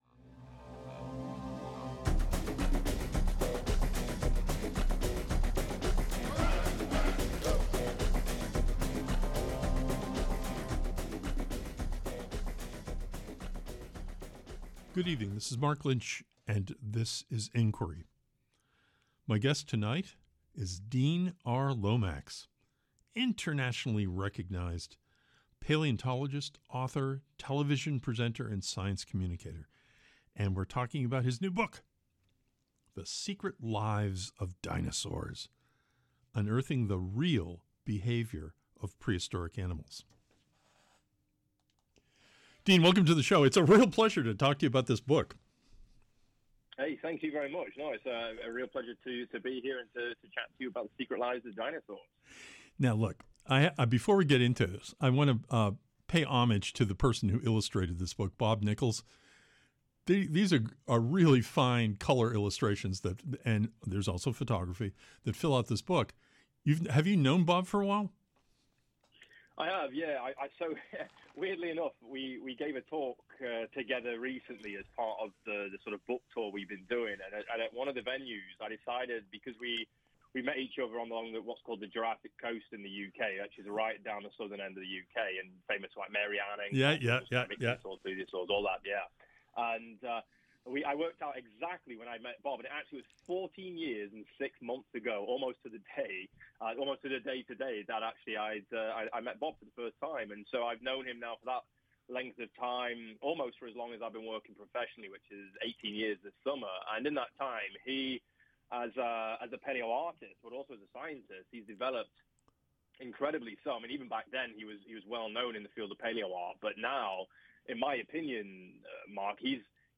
Tonight on Inquiry, we talk with DEAN R. LOMAX, internationally recognized paleontologist, author, television producer, and science communicator. We’ll be taking about his wild and fascinating book THE SECRET LIVES OF DINOSAURS: UNEARTHING THE REAL BEHAVIORS OF PREHISTORIC ANIMALS.